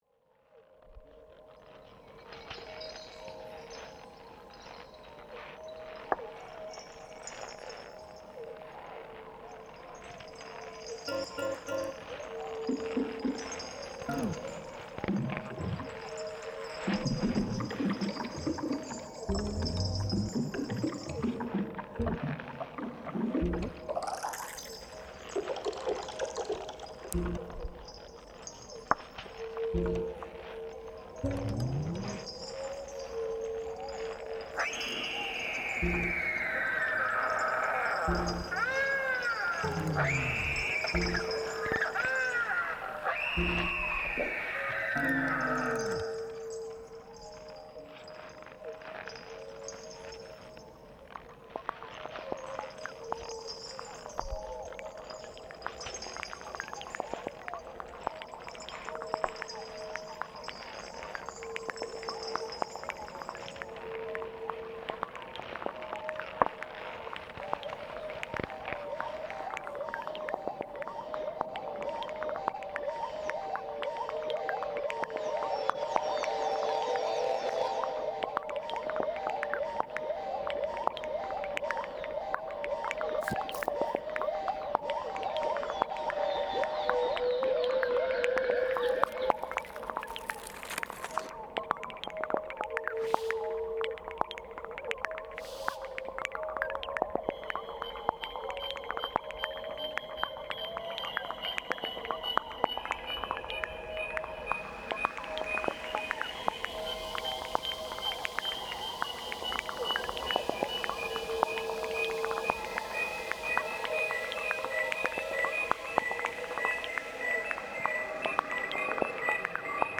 une musique improvisée